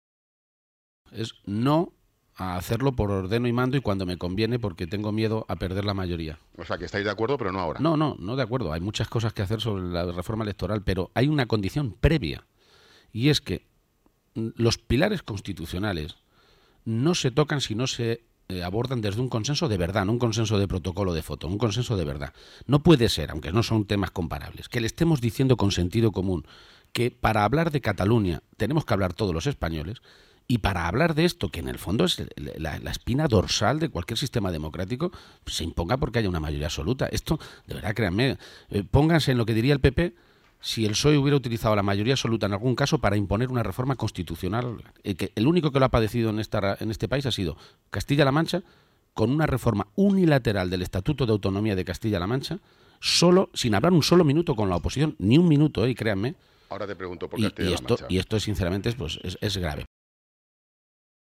García-Page se ha pronunciado de esta manera esta mañana, durante una entrevista en el programa La Mañana de la Cadena Cope, conducido por Ángel Expósito, cuando se le ha preguntado por el momento en el que anunciará su candidatura a la Presidencia de la Junta de Comunidades de Castilla-La Mancha.
Cortes de audio de la rueda de prensa